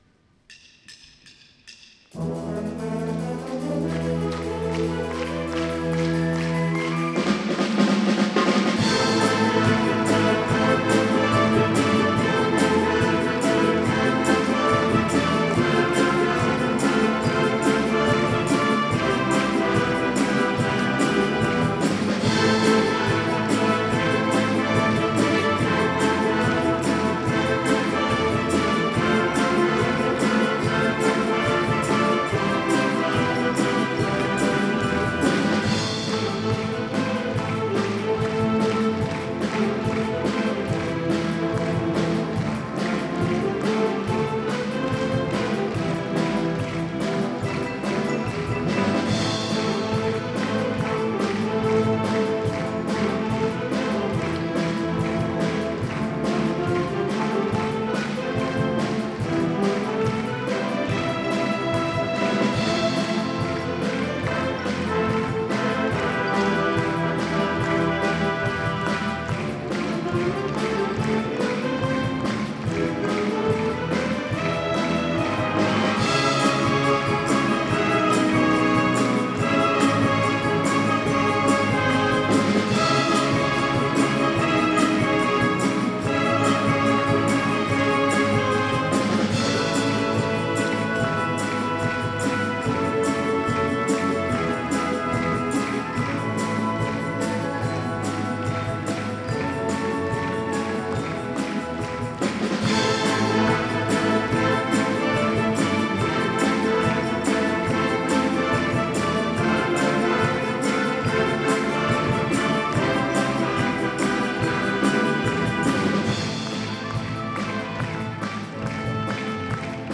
東海大会を決めた仲間たちに壮行の演奏です。
壮行会